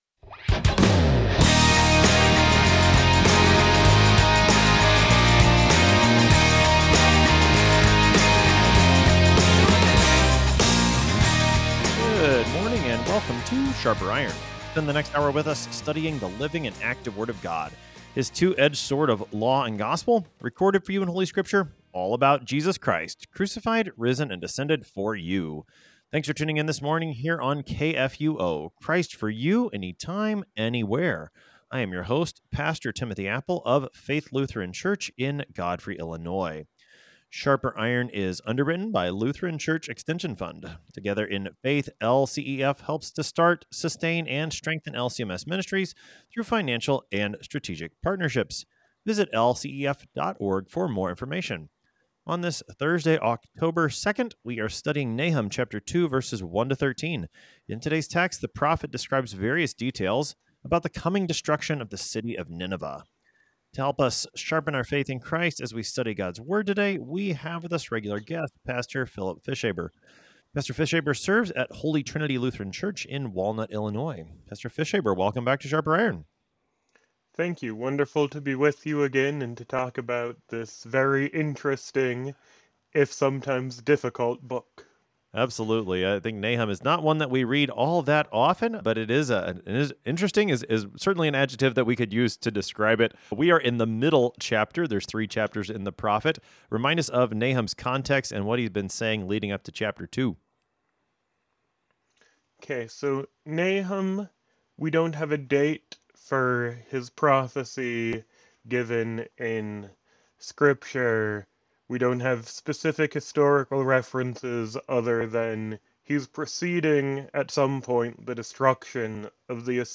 In this engaging conversation